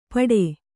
♪ paḍe